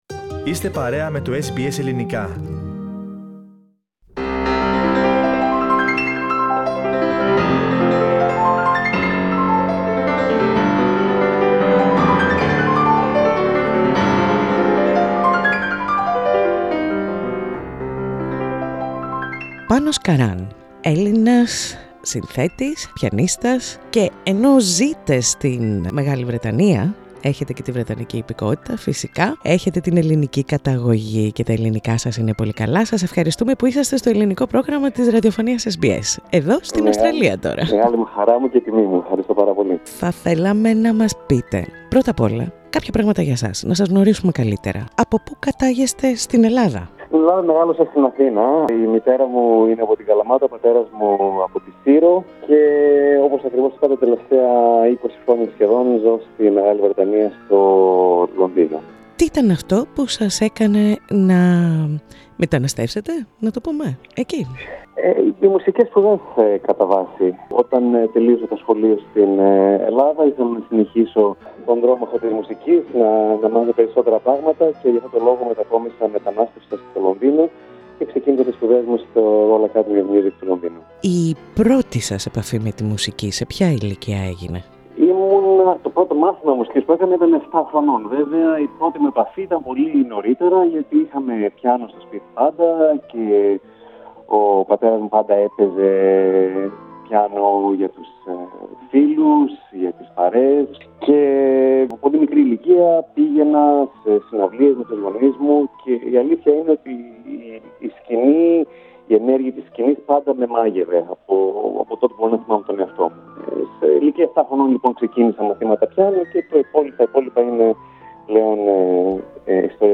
SBS Greek